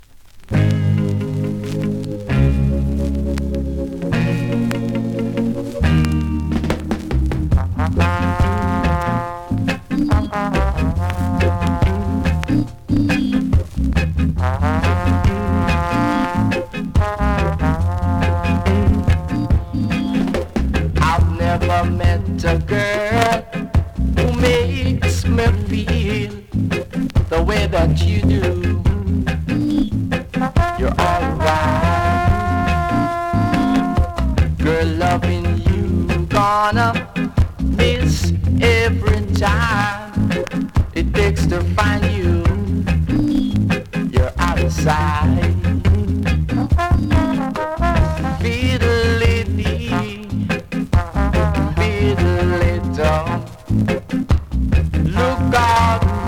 2026!! NEW IN!SKA〜REGGAE
スリキズ、ノイズ比較的少なめで